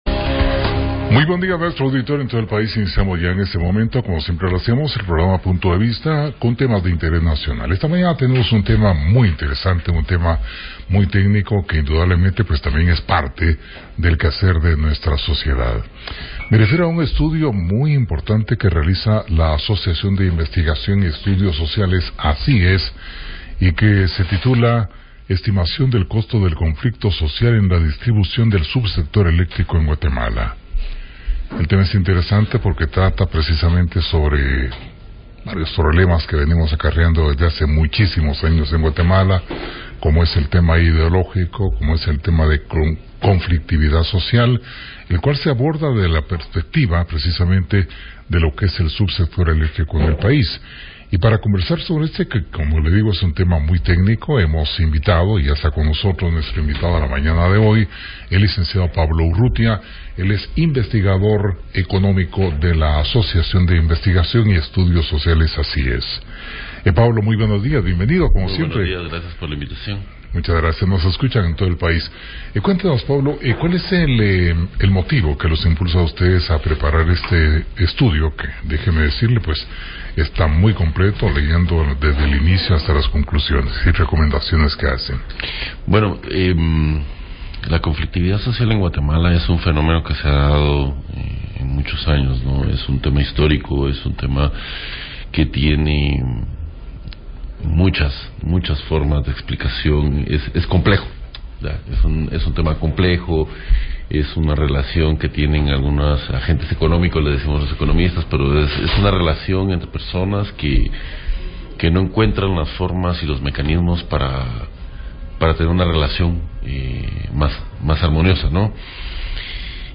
PUNTO DE VISTA / RADIO PUNTO: Entrevista con